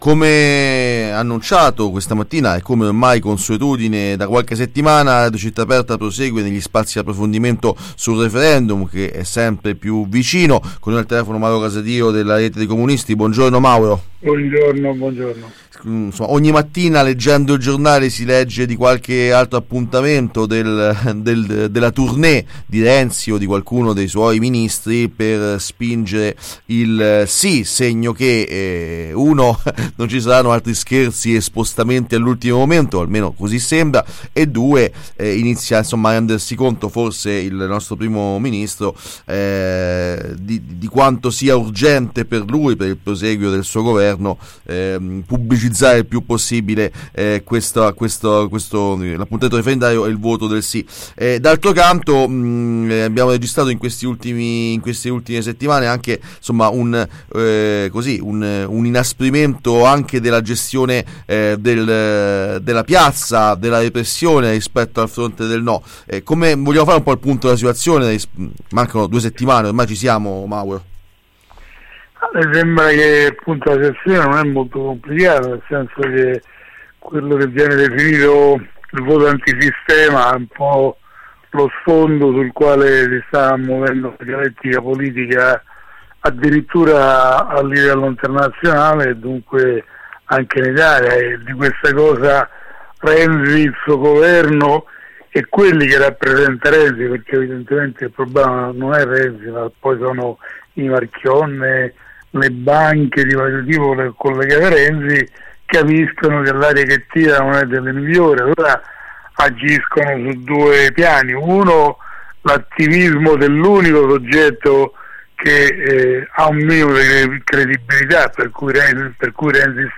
Referendum: intervista